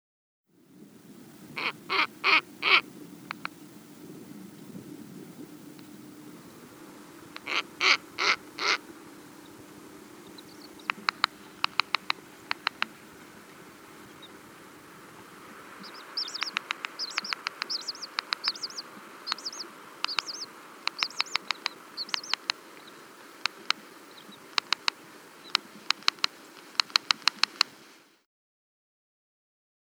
Snowy Owl
The low, powerful, rasping calls of the snowy owl can be heard up to seven miles away on the tundra. Hoots are given two at a time, but can include up to six in a series. When agitated, they will snap their bill and make a clacking sound.
snowy-owl-call.mp3